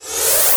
VEC3 Reverse FX
VEC3 FX Reverse 54.wav